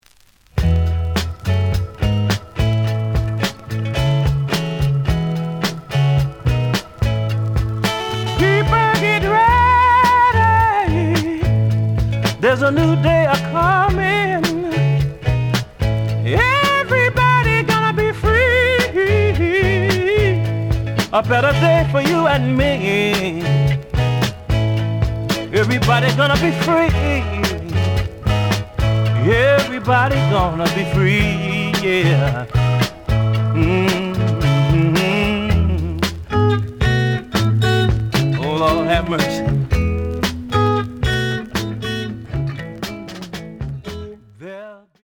The audio sample is recorded from the actual item.
●Genre: Soul, 70's Soul
Looks good, but slight noise on both sides.)